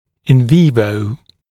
[ɪn ˈviːvəu][ин’ви:воу]в организме, на живом организме, в естественных условиях